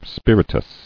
[spir·it·ous]